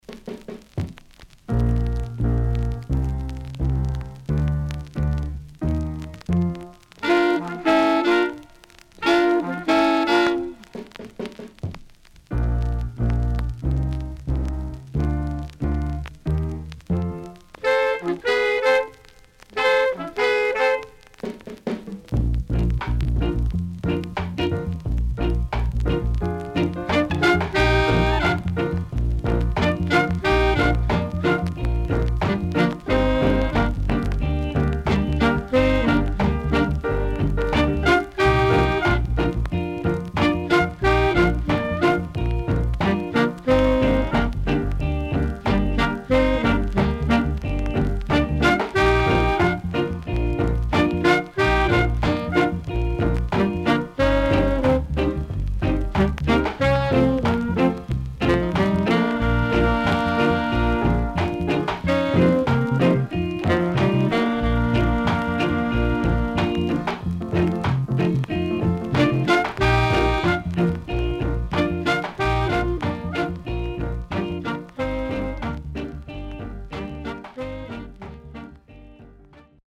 Rocksteady Foundation & Horn Inst
SIDE A:プレス起因によるヒスノイズあります。少しプチノイズ入ります。